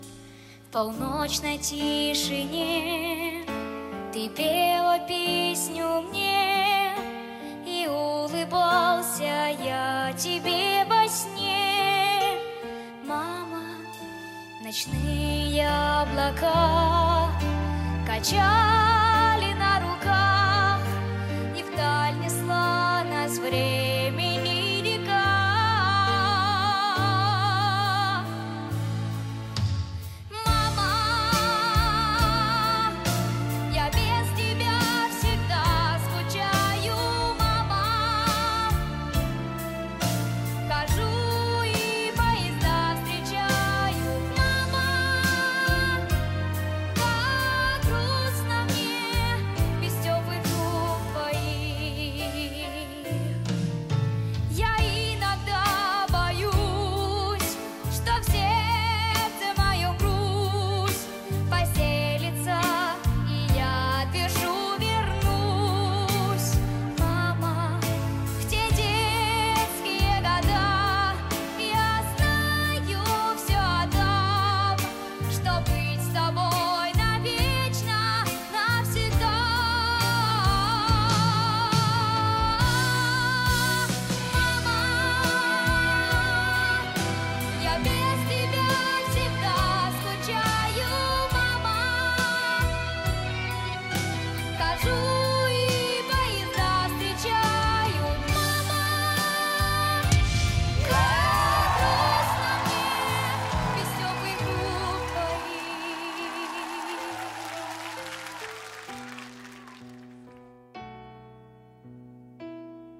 • Категория: Детские песни
детское исполнение